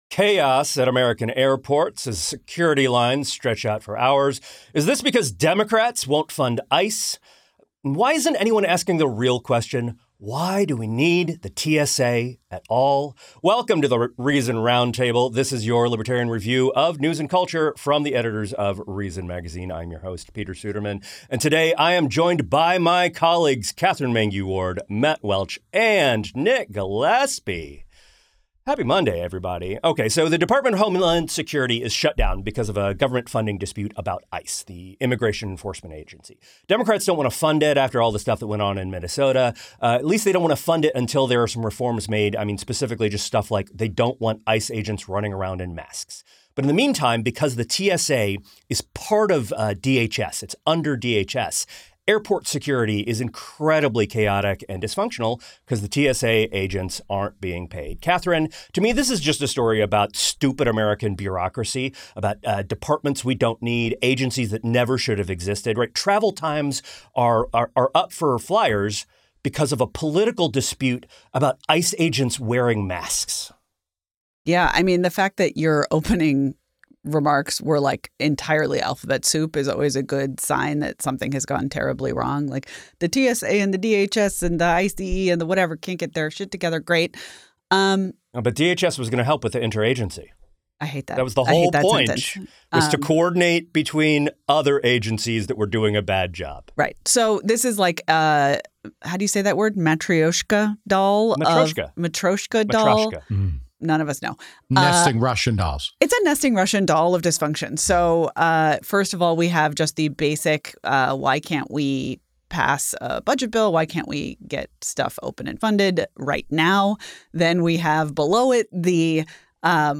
The Reason Roundtable